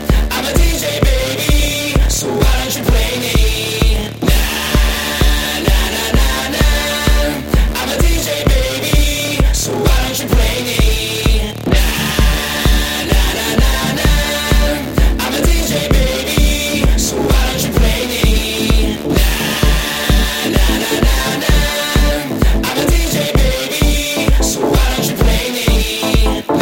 Категория: Клубные рингтоны